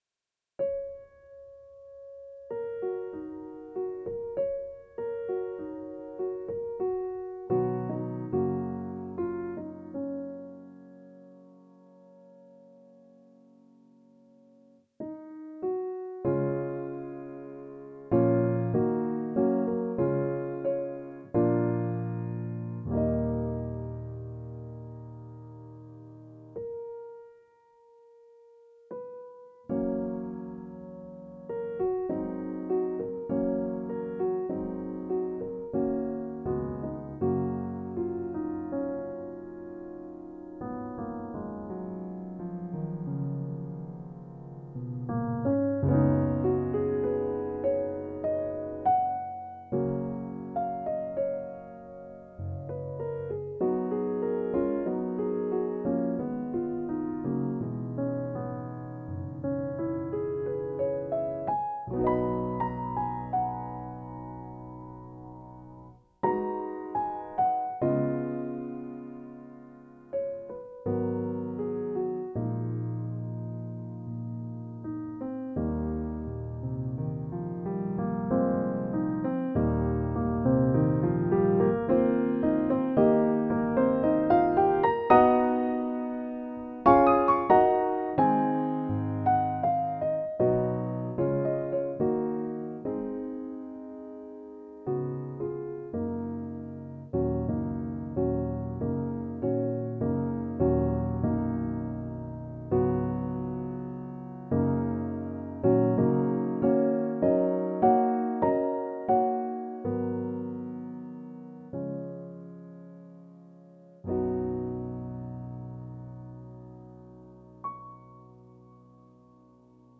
Clasical music